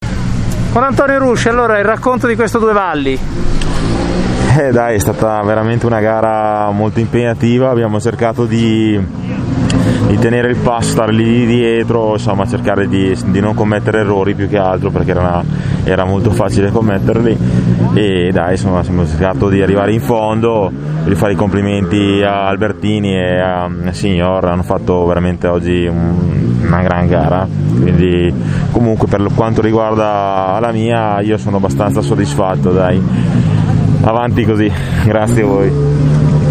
Interviste al Rally Due Valli
Interviste di fine rally